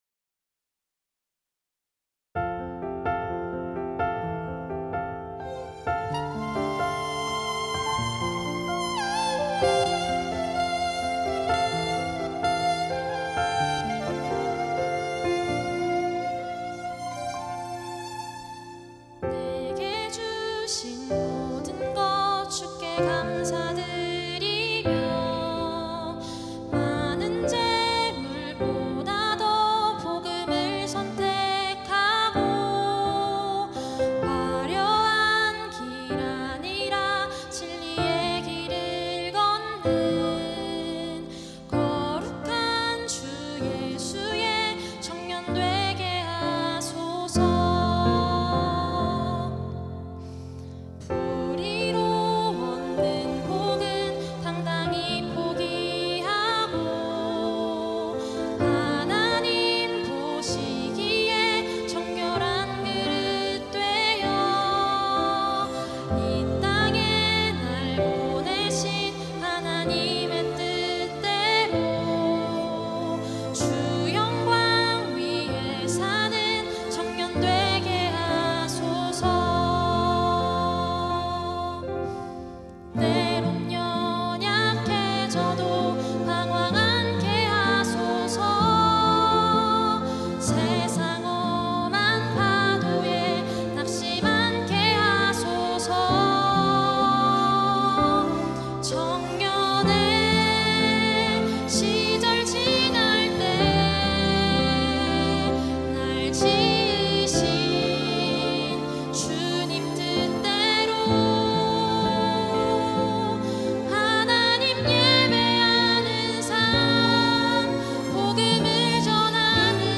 특송과 특주 - 청년의 기도